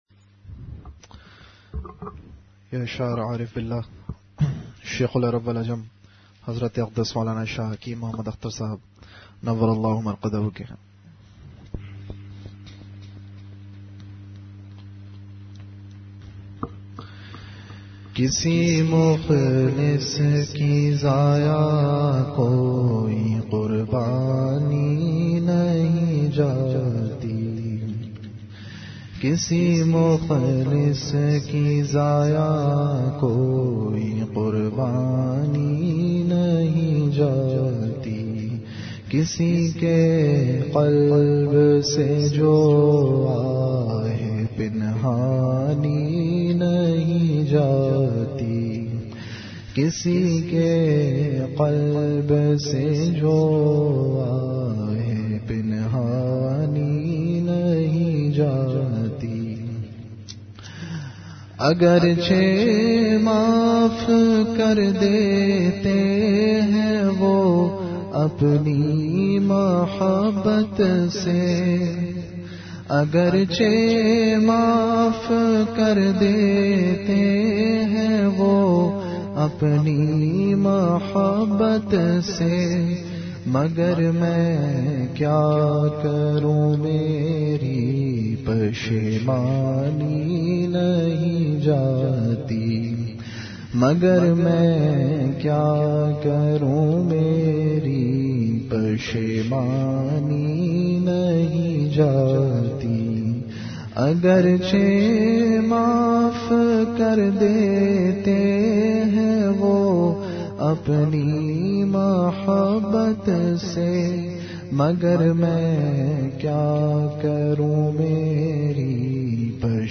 An Islamic audio bayan